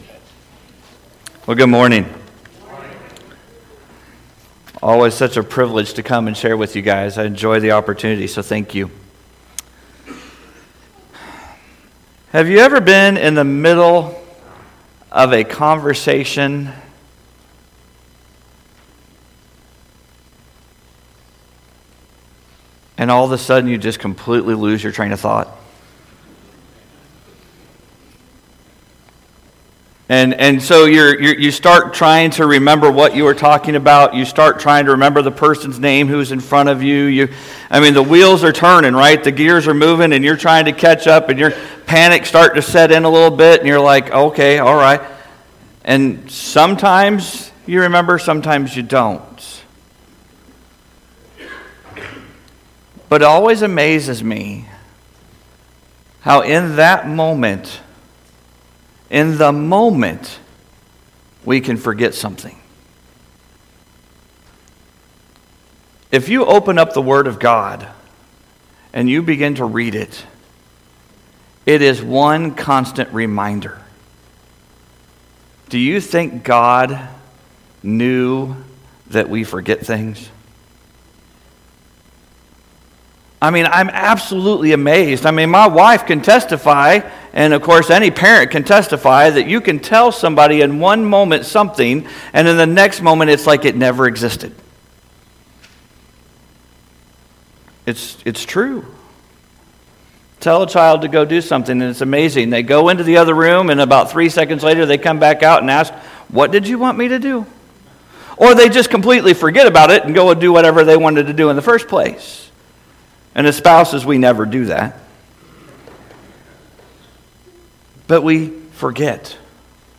Sunday-Service-1-12-25.mp3